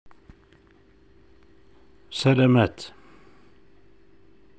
سلامات سَـلَامَـات ْ salamate word in Algerian كلمة تستخدم في بعض مناطق الجزائر .